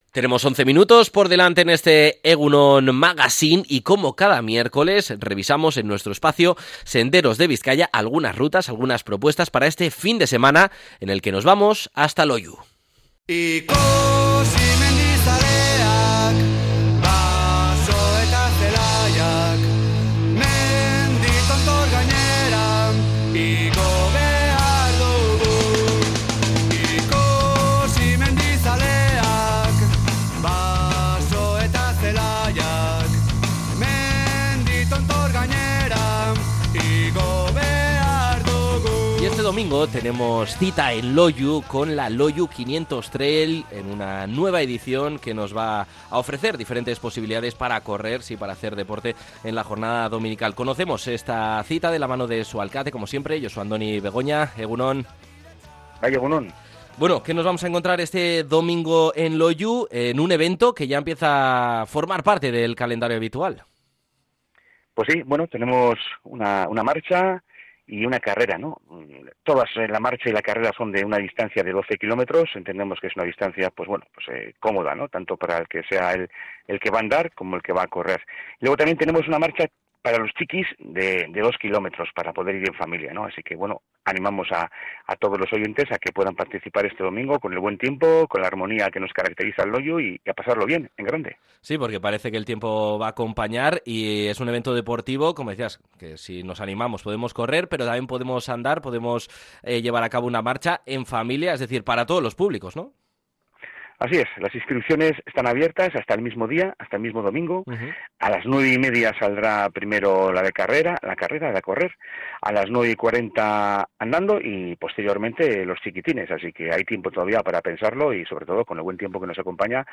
El alkate de Loiu, Josu Andoni Begoña, anima a disfrutar de la segunda LOIU 500 Trail este domingo. Evento deportivo de 12 km en las modalidades de correr y andar.